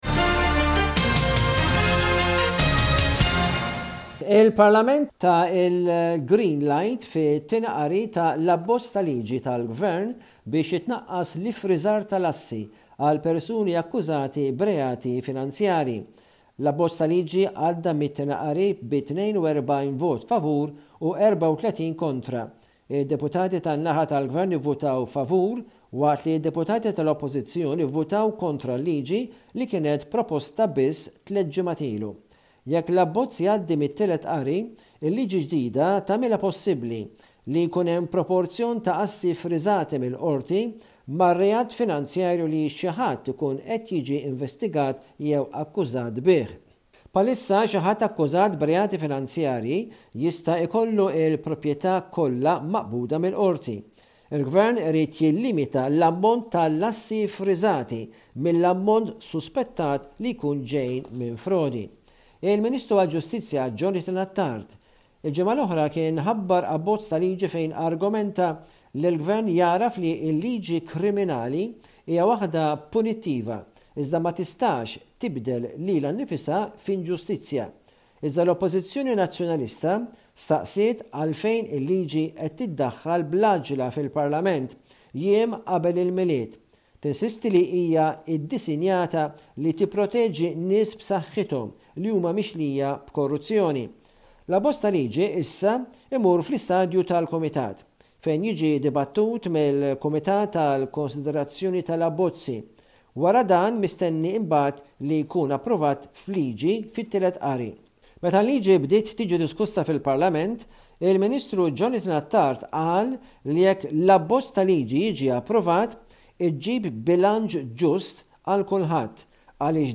News report from Malta